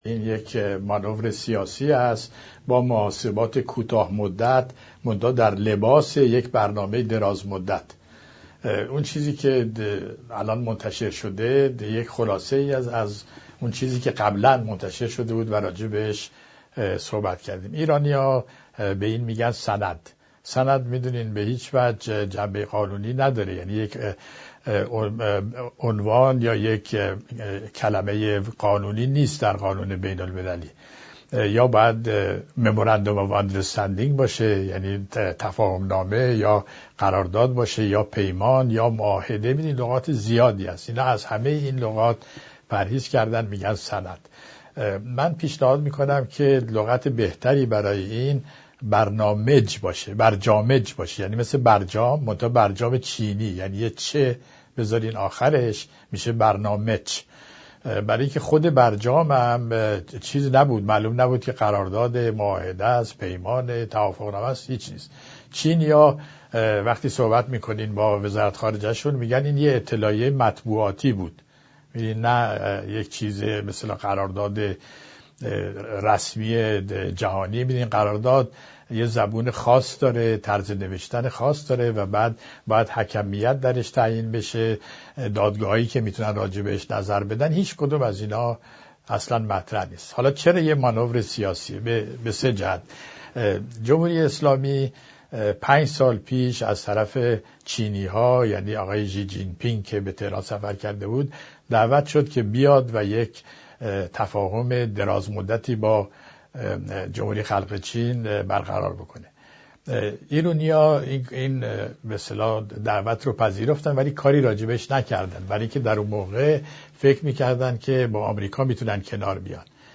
چنانکه قولش را دادم، پادکست دیگری برای بازتاب ویژه به صورت گلچینی از بیانات اخیر استاد طاهری در برنامه ایران فردا برایتان مهیا کرده‌ام.